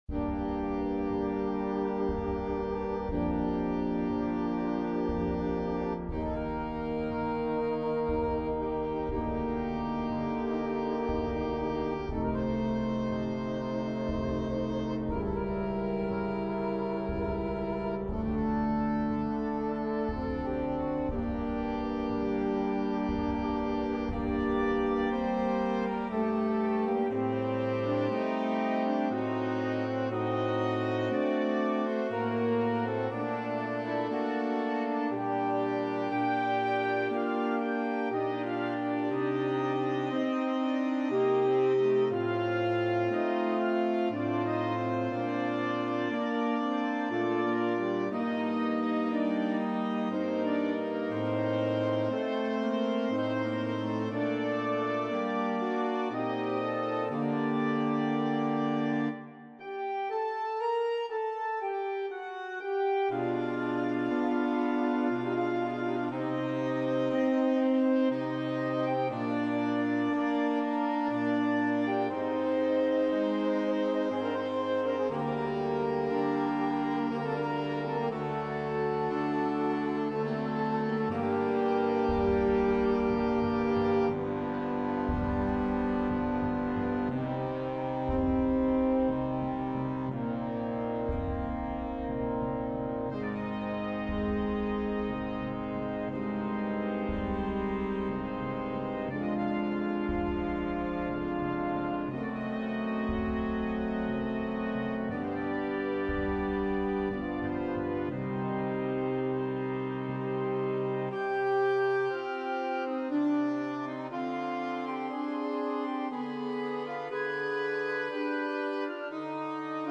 Unendlich traurig und trotzdem unendlich schön.